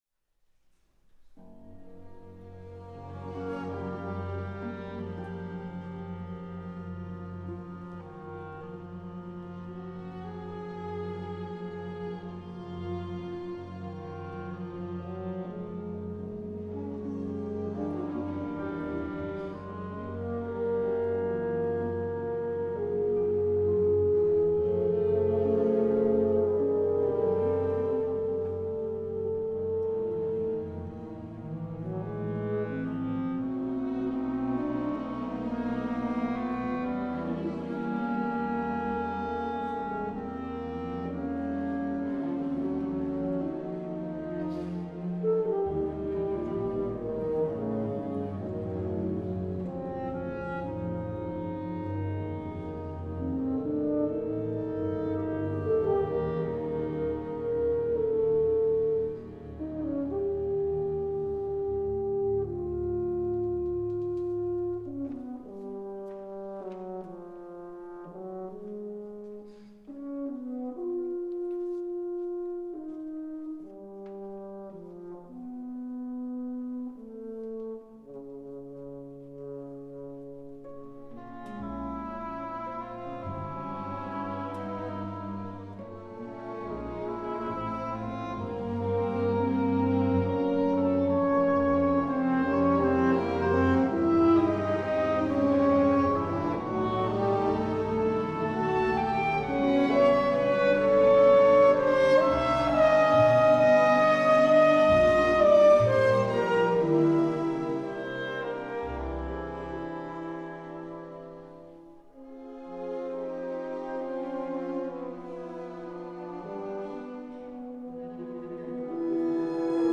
solo horn
mp3 recording of the première in Olomouc
(recording made for Czech Radio Vltava)